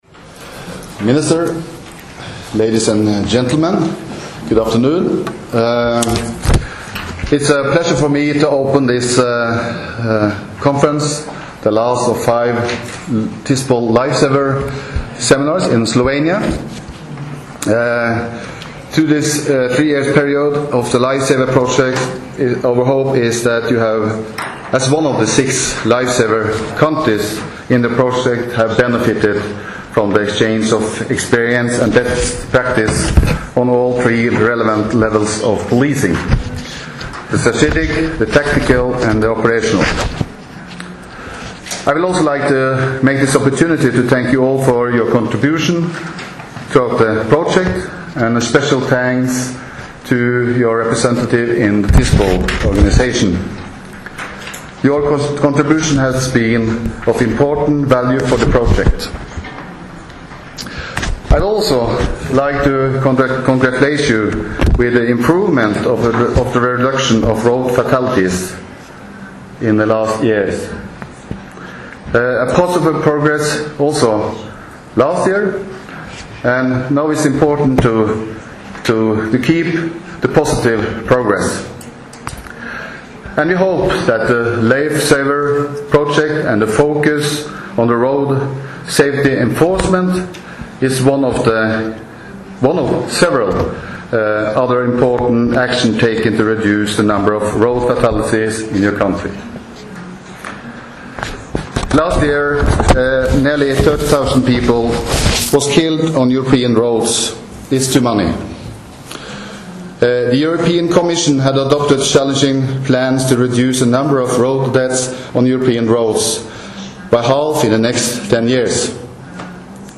Na Bledu se je začel mednarodni strateški seminar o prometni varnosti
Zvočni posnetek nagovora